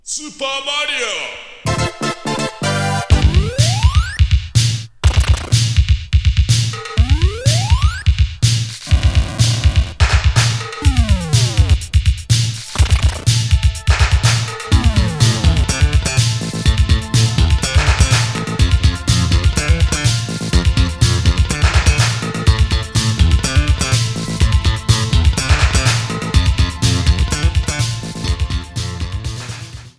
Bass
Chorus
Keyboards
Synthesizer
Vocals
fade out of 3 second